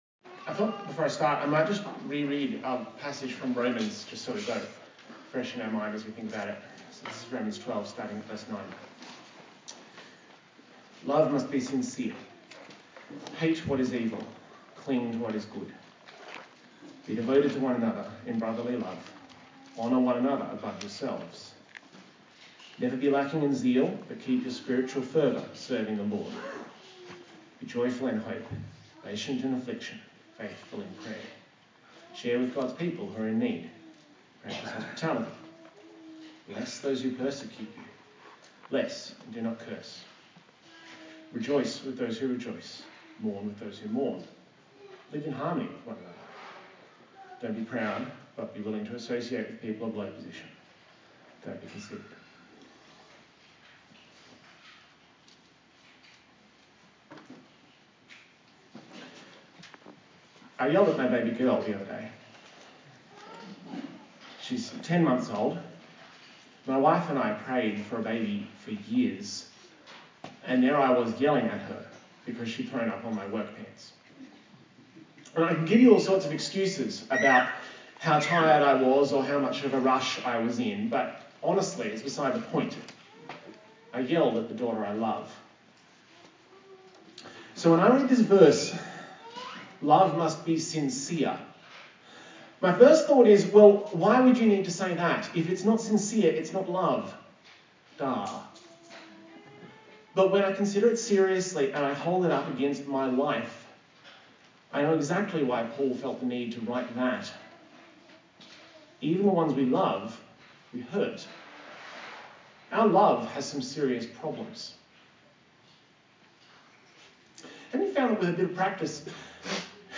Romans Passage: Romans 12:9-16 Service Type: Sunday Morning